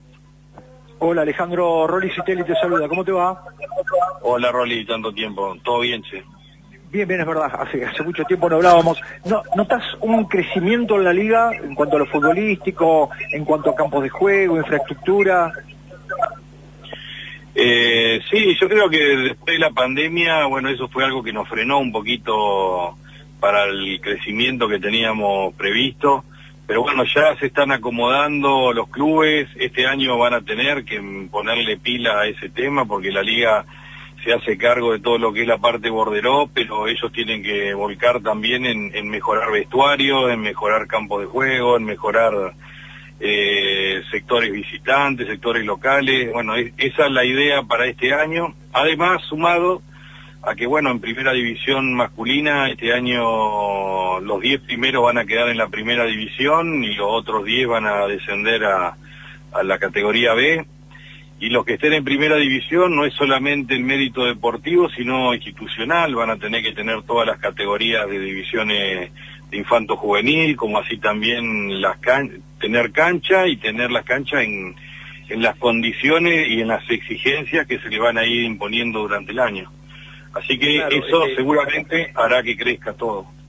Linda charla